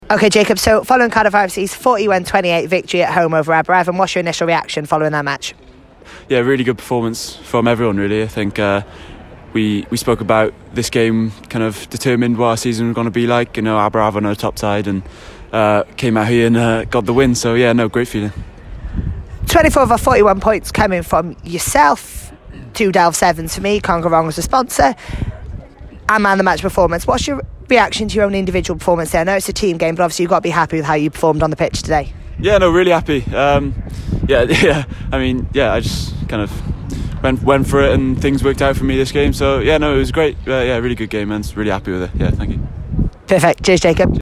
Post-Match Interviews